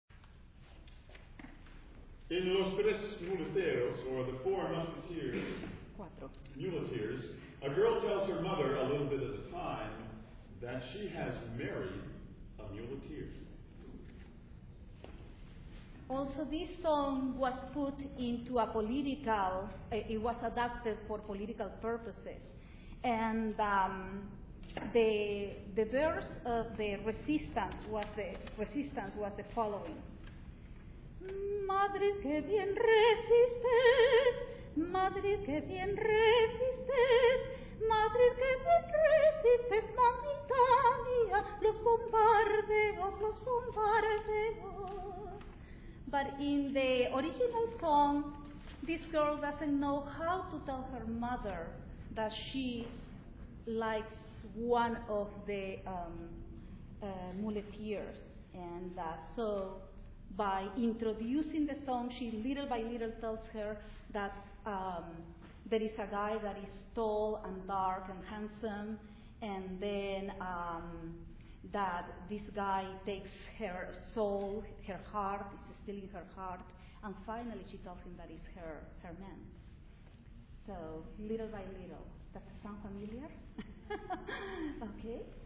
Panasci Chapel at LeMoyne College, Syracuse, NY October 24, 2006